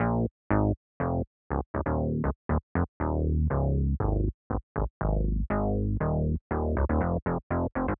17 Bass PT1.wav